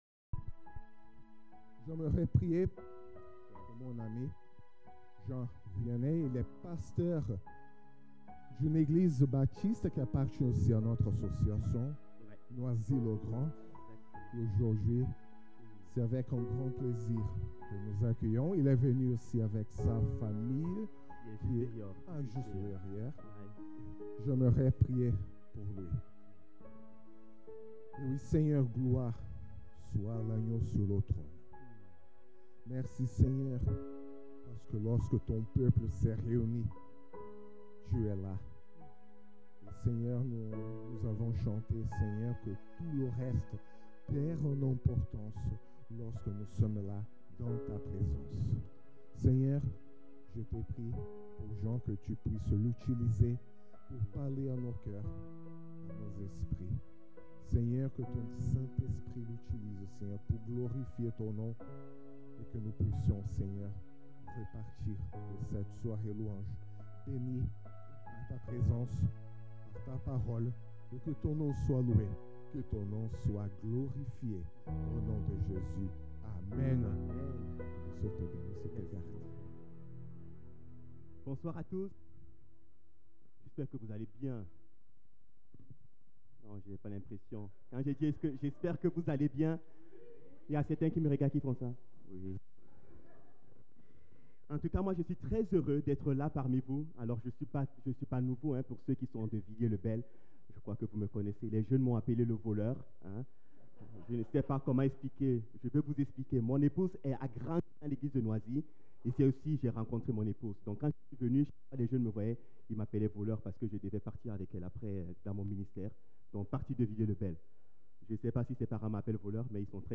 Le samedi 4 octobre 2025, l’église a organisé une soirée louange sur le thème Coeur à Coeur.